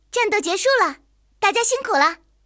野牛战斗返回语音.OGG